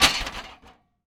metal_hit_small_01.wav